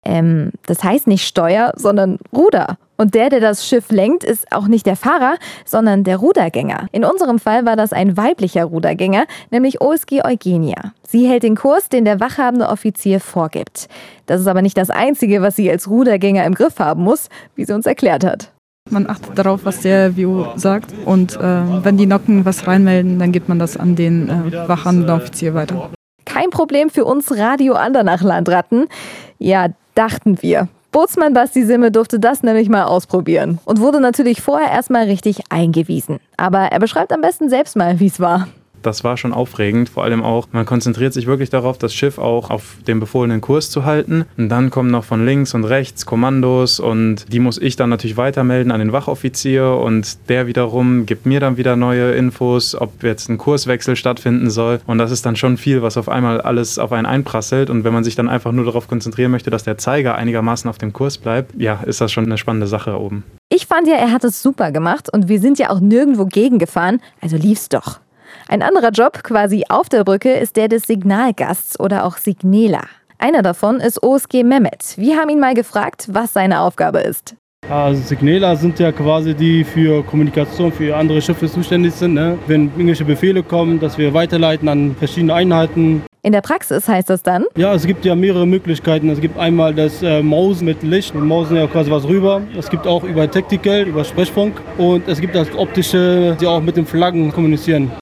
Unsere Landratten von Radio Andernach durften tatsächlich auch das Steuer übernehmen. Wie sich das angefühlt hat, das erklären die beiden hier.